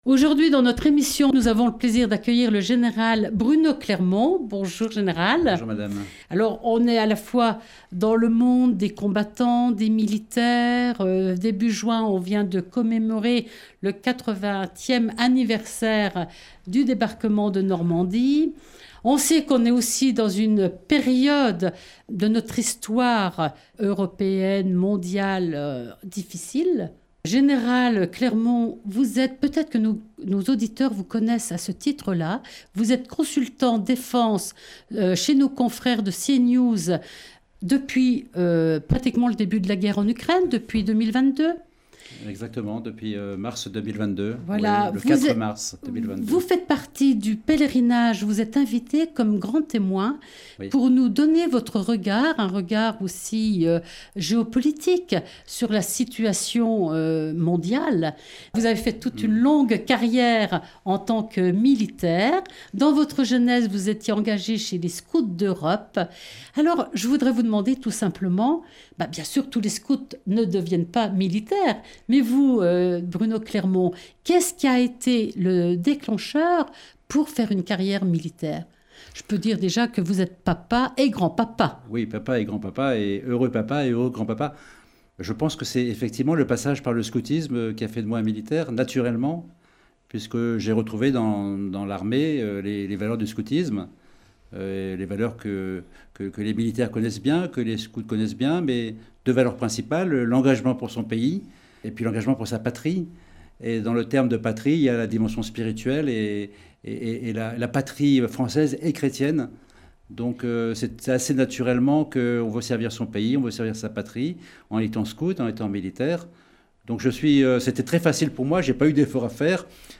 jeudi 27 juin 2024 Le grand entretien Durée 11 min